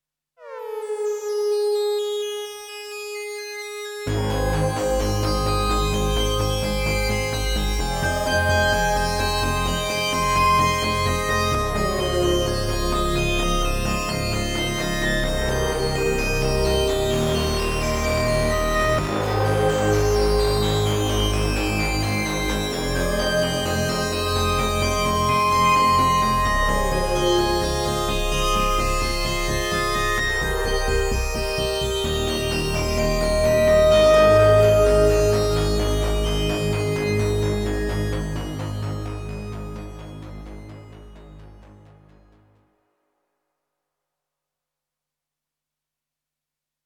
MT SYNCED SAW PAD | Supercritical Synthesizers
MT-SYNCED-SAW-PAD.mp3